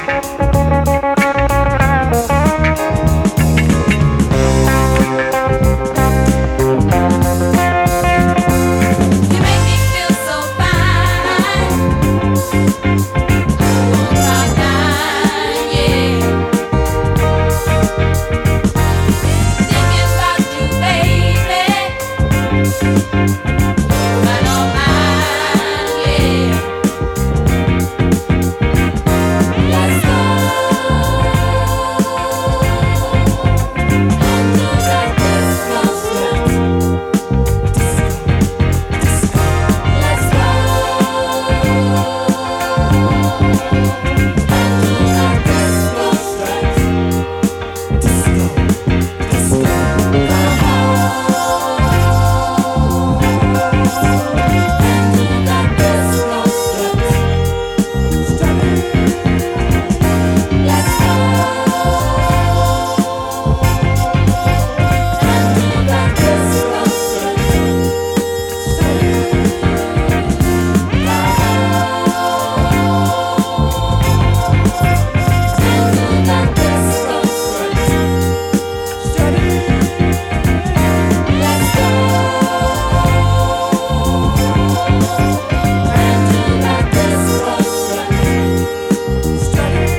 a treasure trove of garage classics and dance classics!